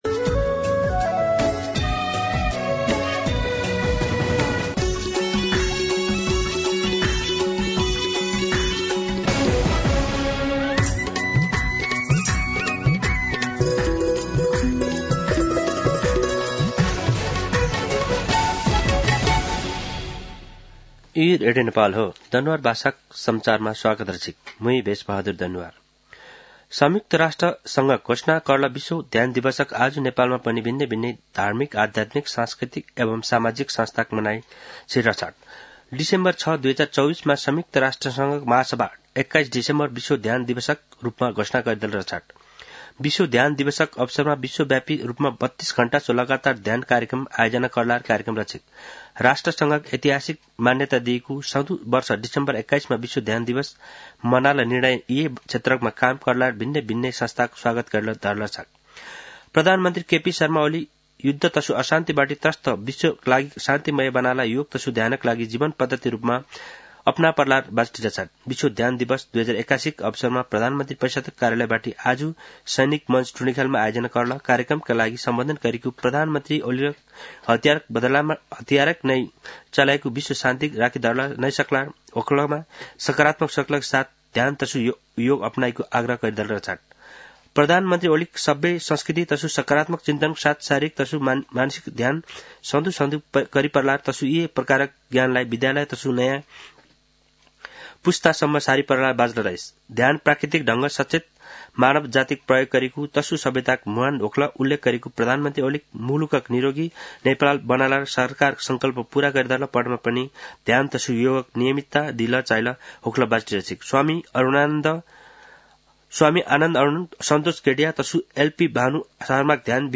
दनुवार भाषामा समाचार : ७ पुष , २०८१
Danuwar-News-06.mp3